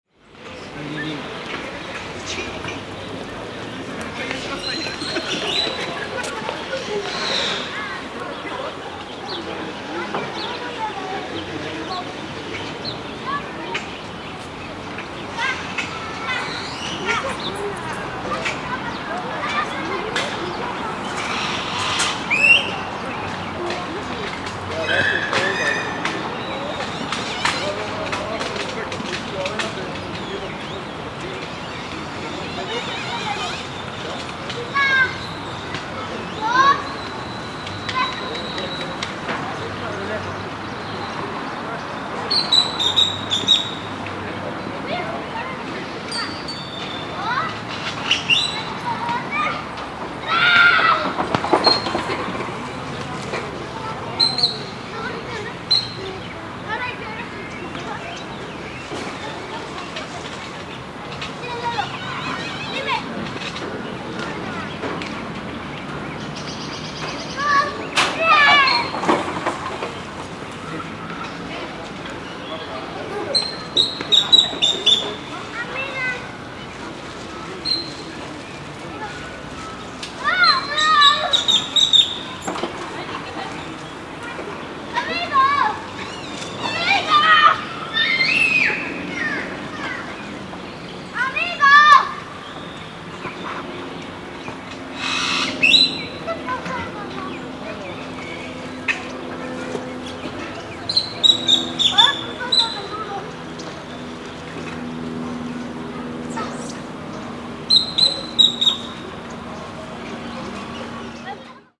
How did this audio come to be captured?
Zocalo in the morning at Puebla's downtown. Mono 44kHz 16bit UNESCO listing: Historic Centre of Puebla